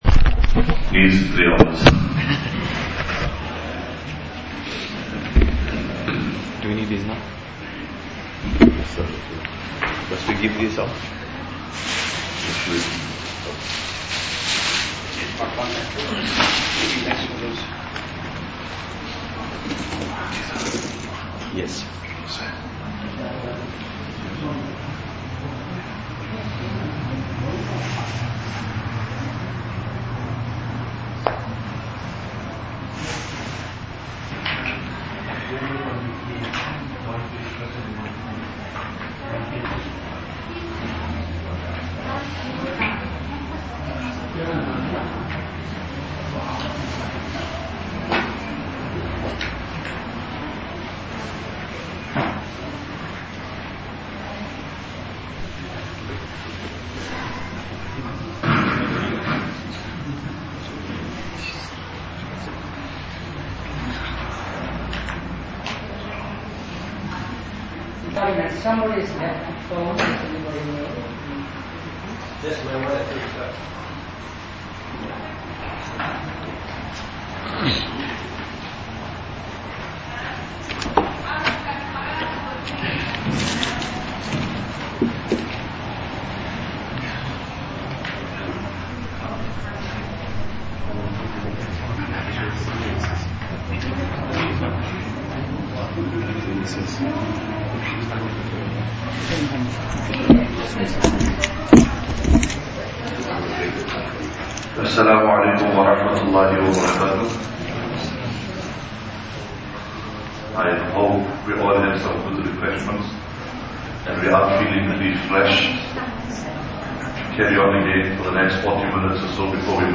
Lecture 3 – Quran Teaching Methodology Part 2 (Saturday 29th April 2017)
Islamic Studies Teachers Professional Development Workshop at Masjid Ibrahim.